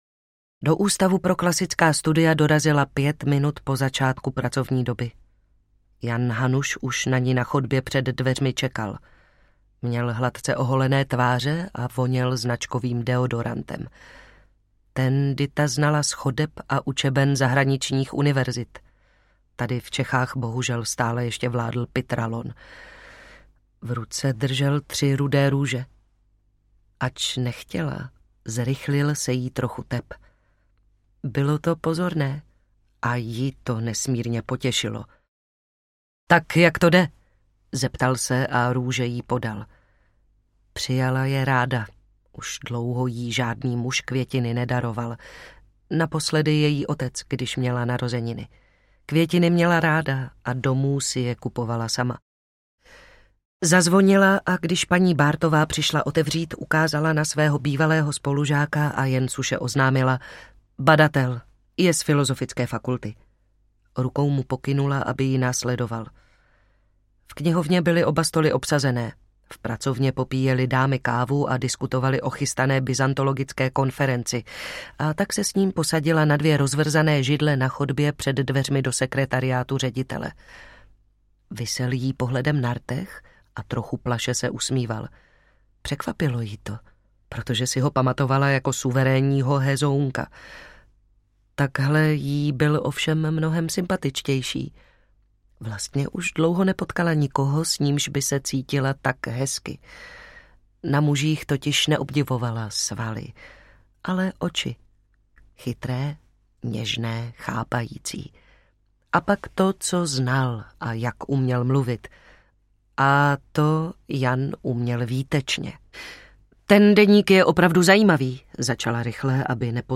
Dědictví templářů audiokniha
Ukázka z knihy
Vyrobilo studio Soundguru.